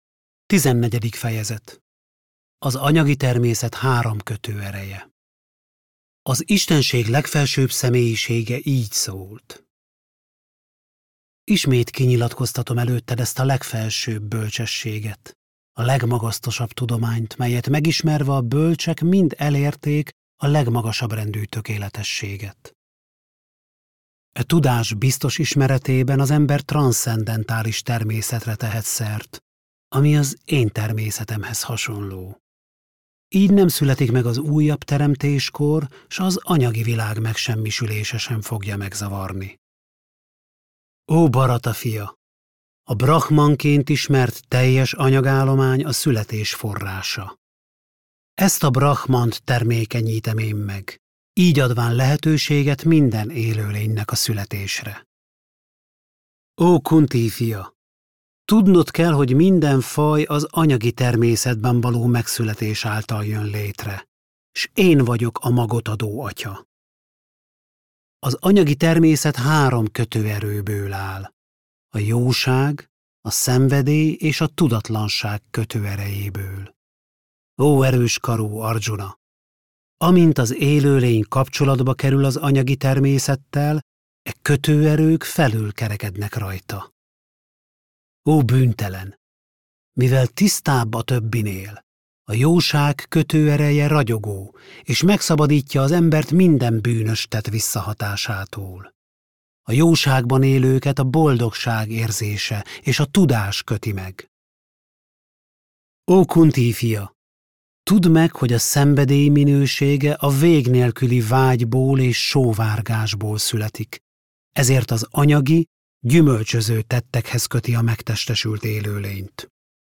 Bhagavad-gītā hangoskönyv – Tizennegyedik fejezet - Magyarországi Krisna-tudatú Hívők Közössége